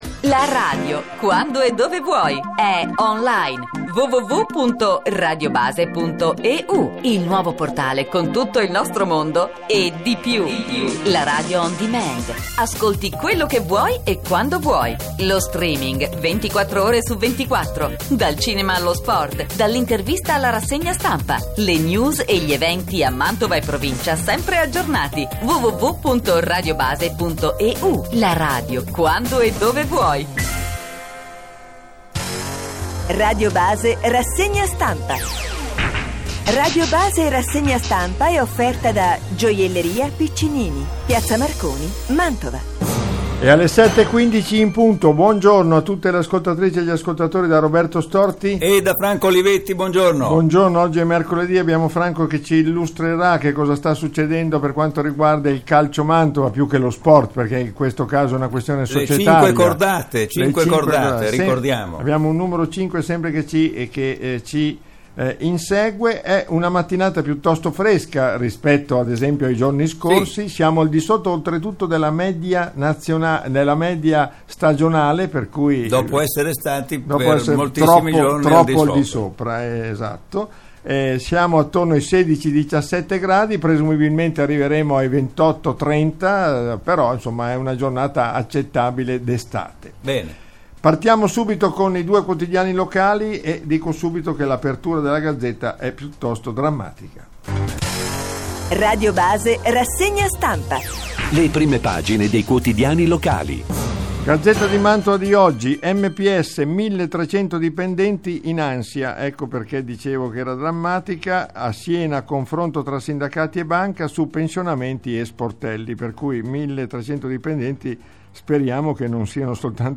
Gazzetta e Voce di Mantova, Il Corriere della Sera, Repubblica, La Stampa, Manifesto e Il Giornale. Leggiamo con voi tutte le prime pagine e le pagine interne dei quotidiani locali.
Rassegna Stampa di mercoledì 26 Luglio